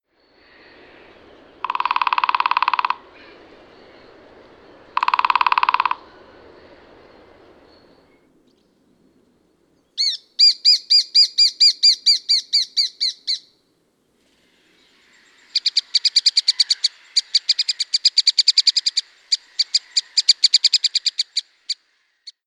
Pikkutikan kiikitys on nopeatempoista ja tasaista, eli ääni ei nouse eikä laske. Tahti voi hidastua hieman lopussa. Yksi sarja koostuu 8-15 tavusta: kii-kii-kii-kii-kii-kii-kii-kii-kii…!
Pikkutikka
Lajin pesimäaika alkaa huhtikuussa, jolloin piskuiset tikat kuuluttavat reviiriään kiikittämällä ja rummuttamalla.